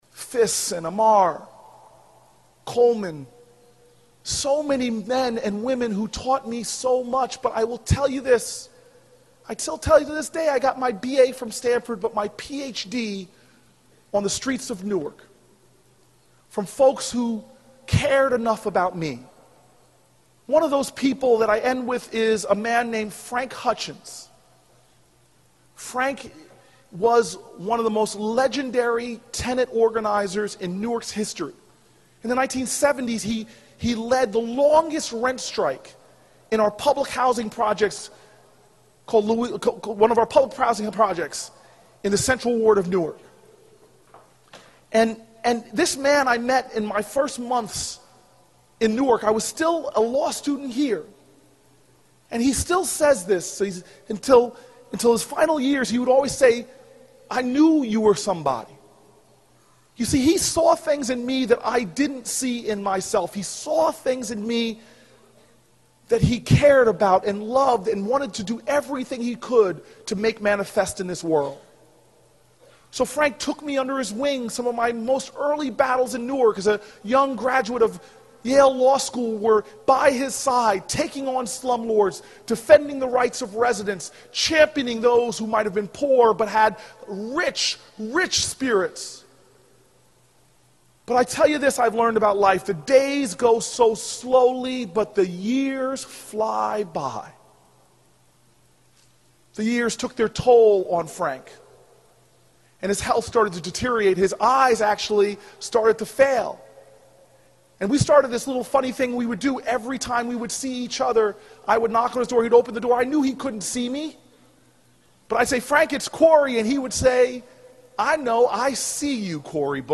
公众人物毕业演讲 第453期:科里布克2013年耶鲁大学(21) 听力文件下载—在线英语听力室